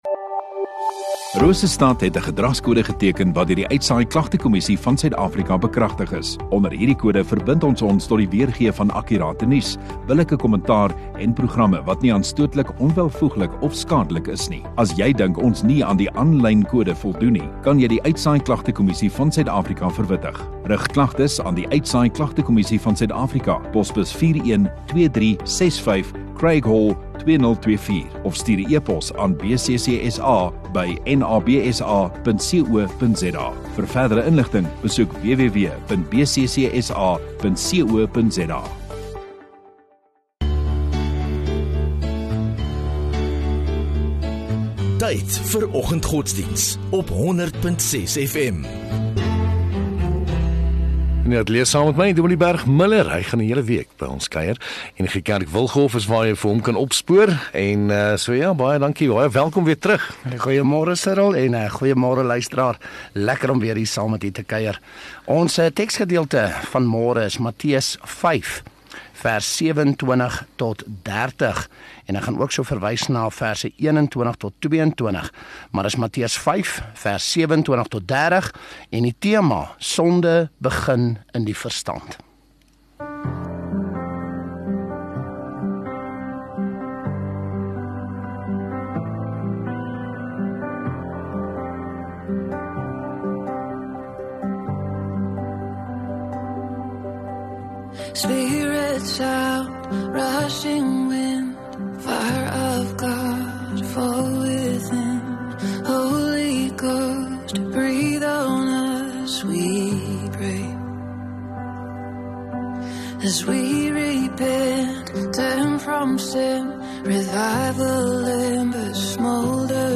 19 Sep Dinsdag Oggenddiens